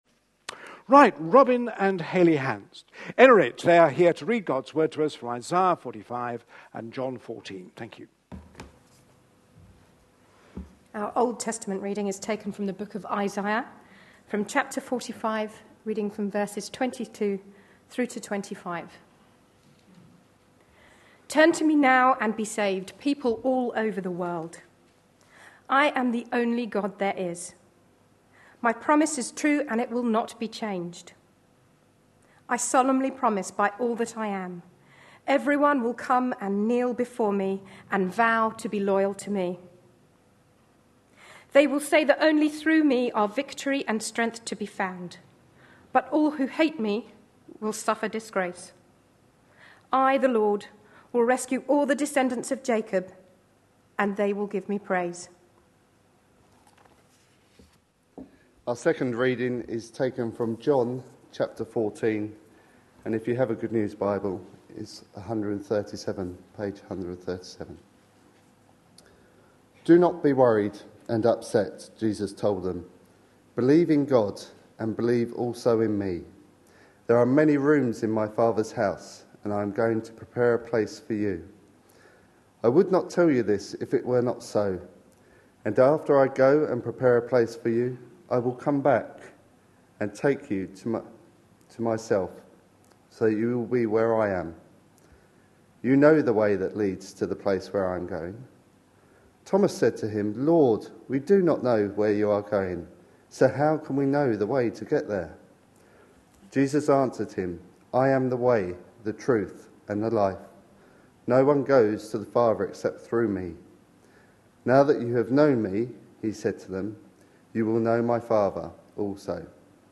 A sermon preached on 22nd September, 2013, as part of our Objections to faith answered! series.